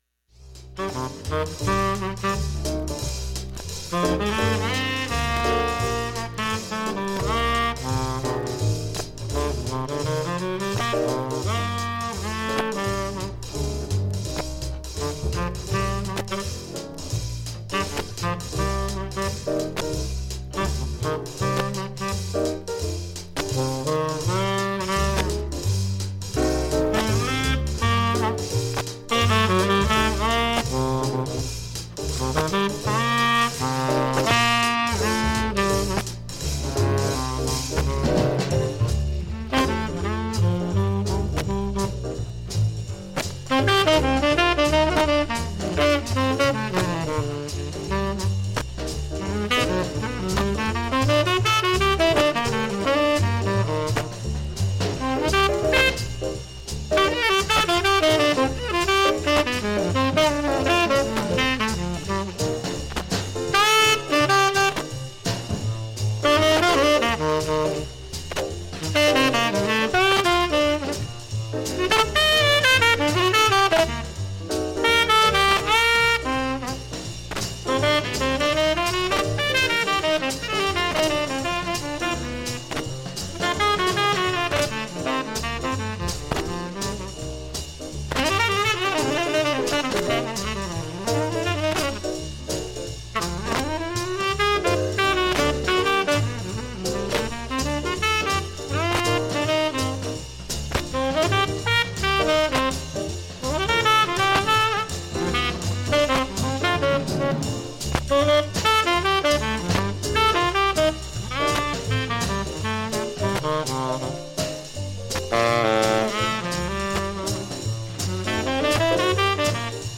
7,(21m40s〜) 現物の試聴（上記録音時間21m40s）できます。
ＵＳＡ盤 Repress, Mono